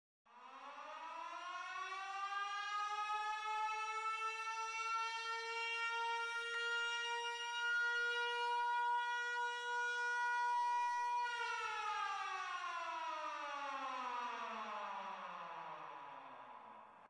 bruitage sirene alarme 1.mp3
bruitage-sirene-alarme1.mp3